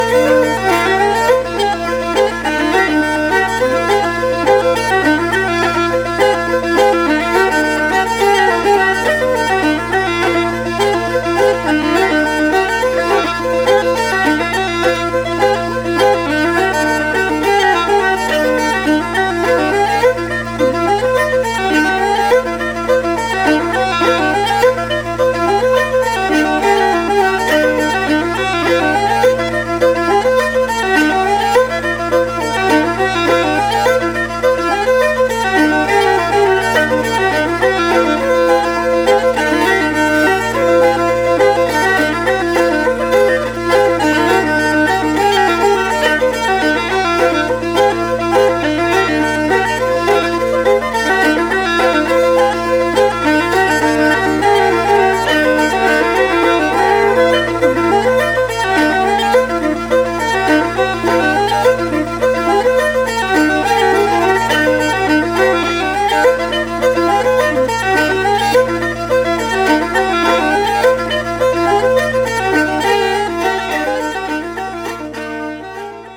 fiddle, hardanger fiddle, whistle